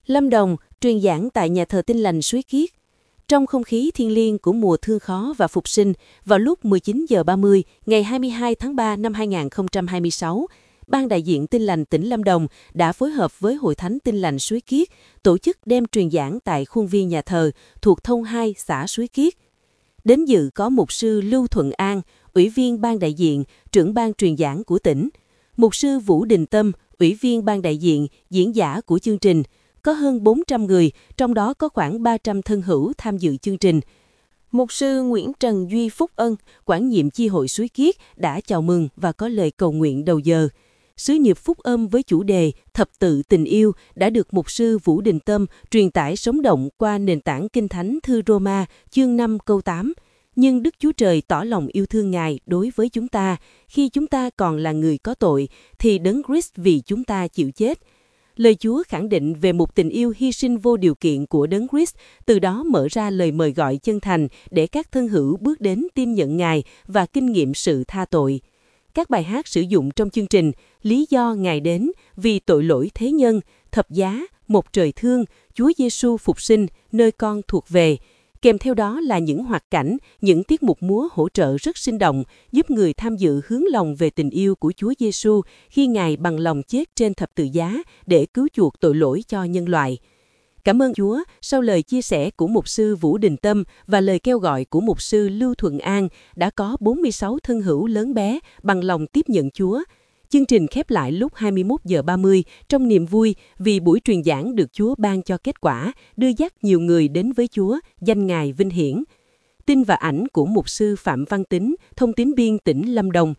Lâm Đồng: Truyền giảng tại Nhà thờ Tin Lành Suối Kiết